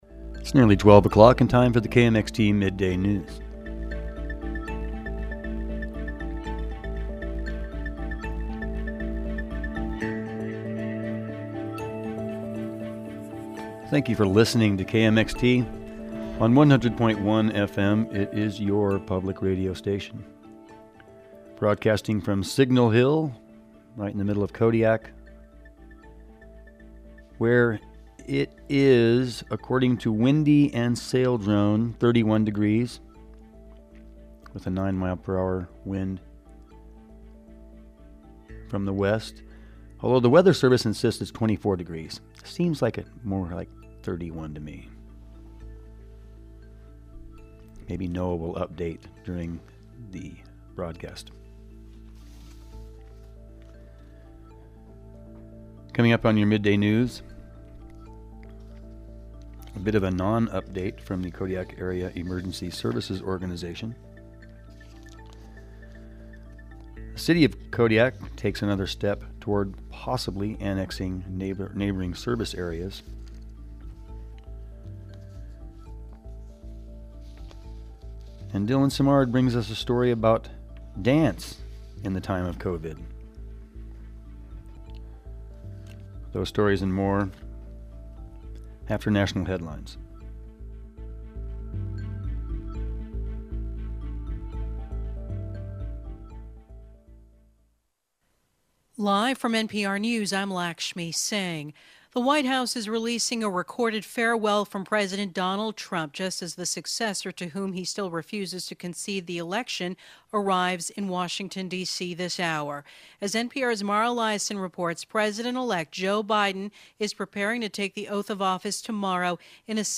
Noon Newscast – Tuesday, January 19, 2020